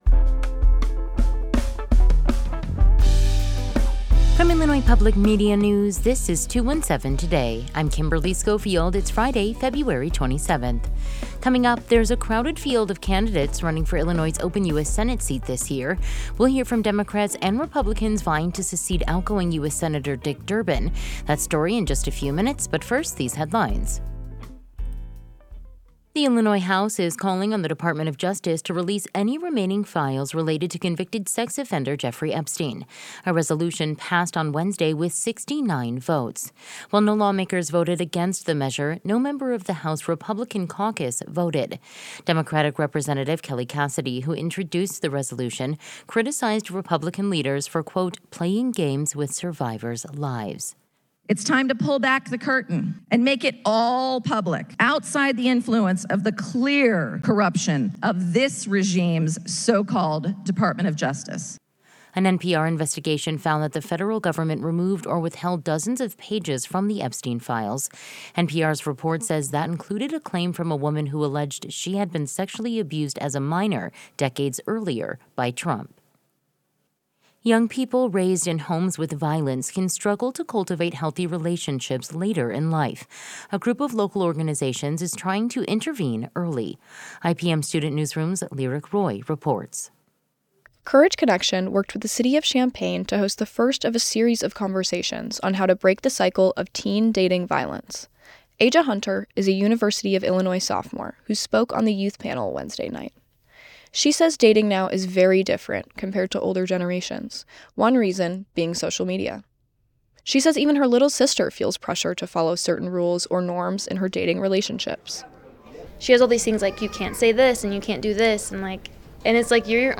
There's a crowded field of candidates running for Illinois' open U.S. Senate seat this year. Immigration enforcement has been a top issue in the race. We'll hear from Democrats and Republicans vying to succeed outgoing U.S. Senator Dick Durbin.